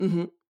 VO_ALL_Interjection_14.ogg